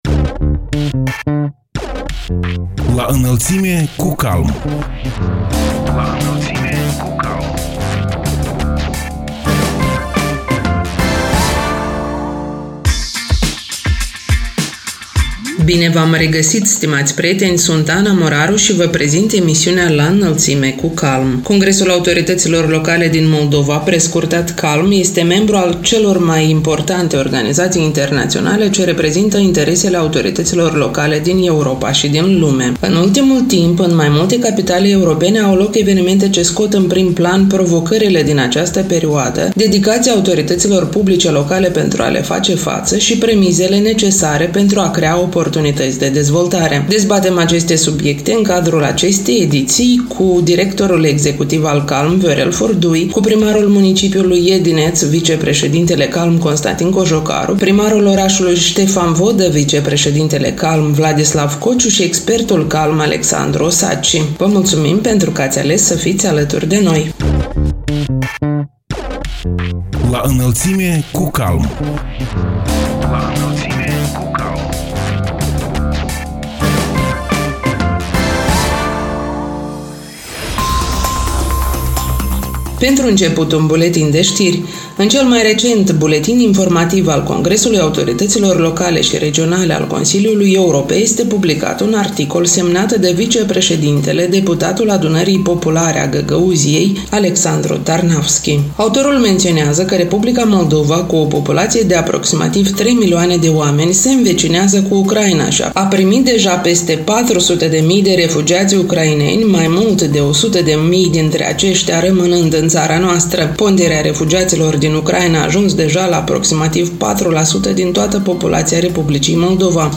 În ultimul timp, în mai multe capitale europene au loc evenimente ce scot în prim-plan provocările din această perioadă, dedicația autorităților publice locale pentru a le face față și premisele necesare pentru a crea oportunități de dezvoltare. Dezbatem aceste subiecte în cadrul emisiunii „La Înălțime cu CALM”.